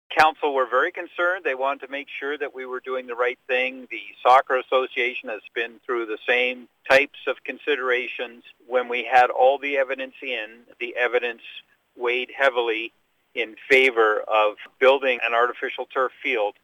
Mayor John Lefebure says it was a carefully thought out decision.